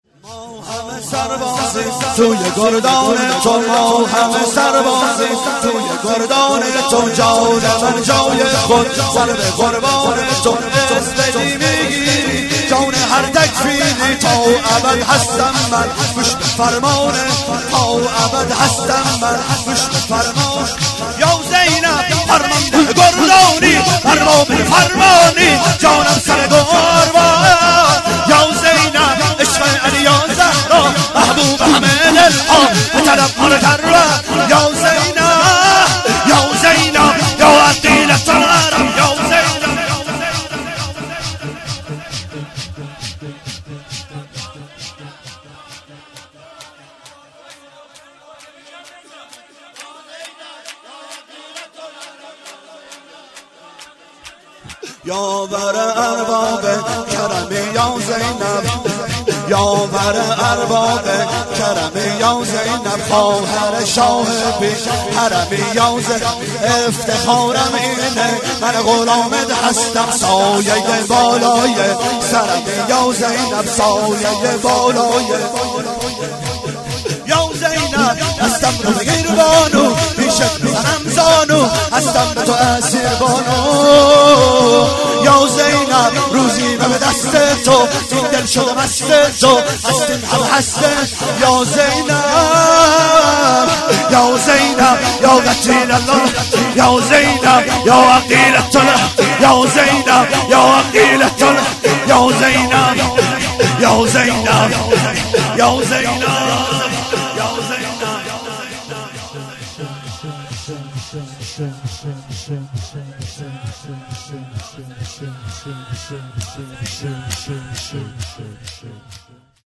شور - ماهمه سربازیم توی گردان تو
جشن ولادت حضرت زینب(س)- جمعه 29 دیماه